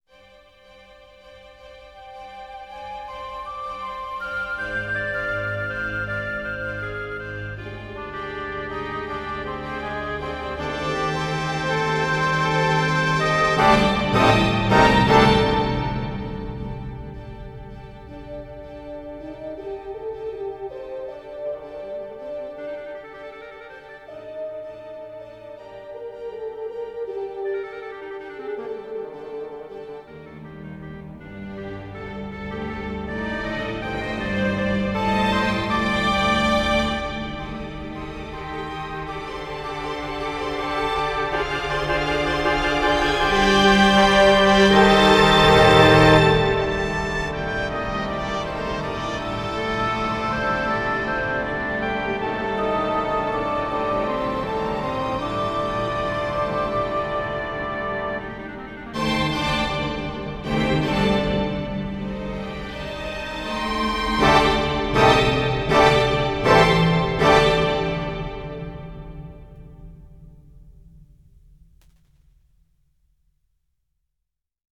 元が丁寧に作ってあることもあるけど、フォントがこんなに良い音を出すとは驚きである！